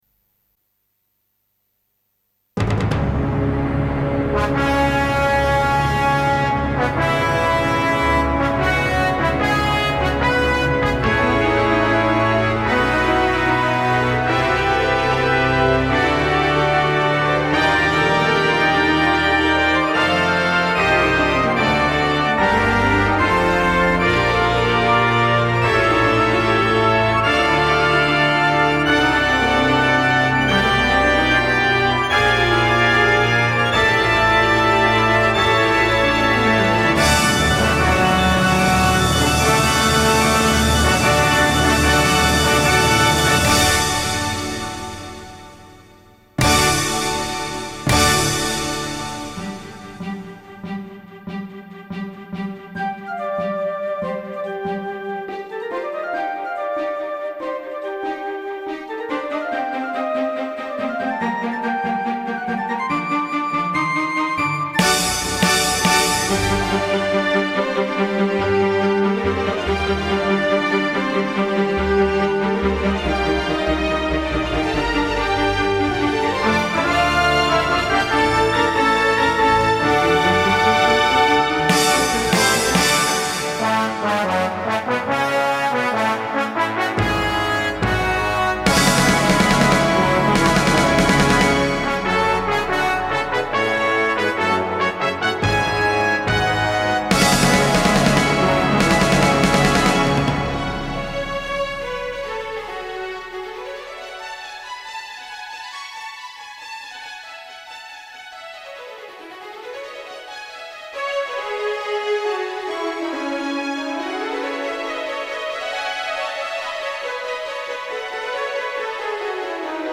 Played by Solo Orchestra